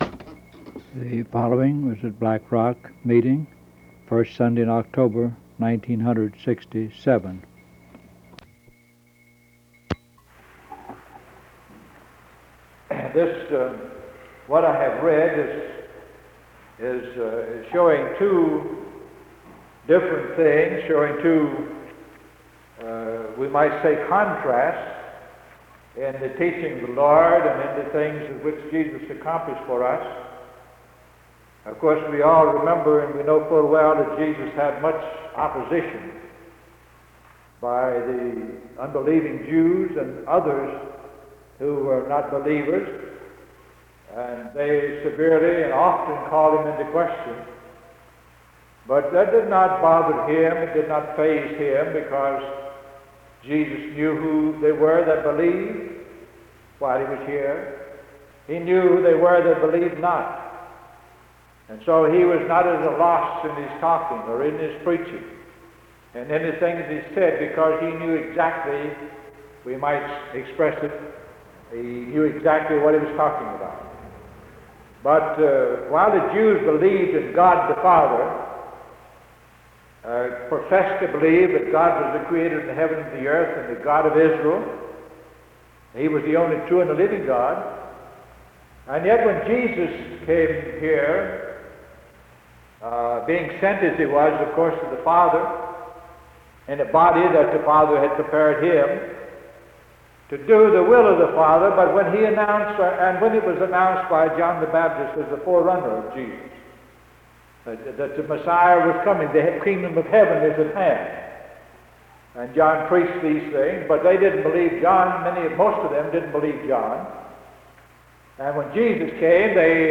Recording does not include the reading of the focus text
Location Butler (Md.) Baltimore County (Md.)